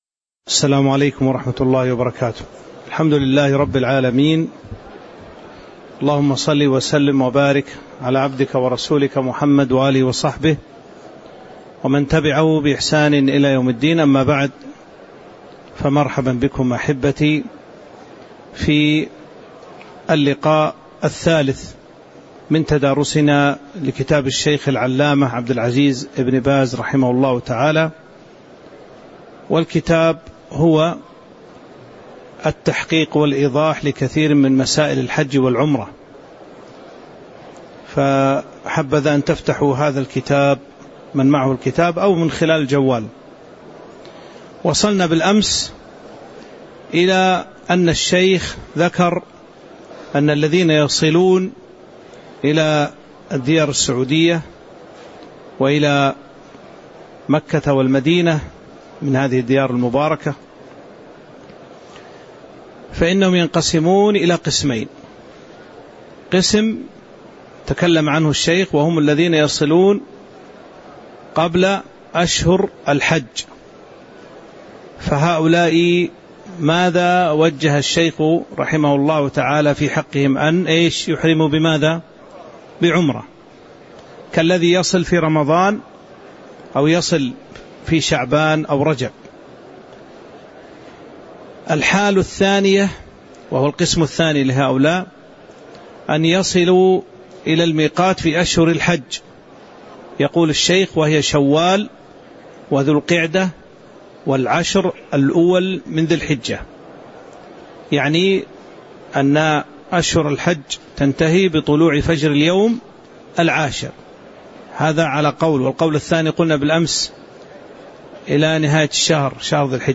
تاريخ النشر ٢٢ ذو القعدة ١٤٤٦ هـ المكان: المسجد النبوي الشيخ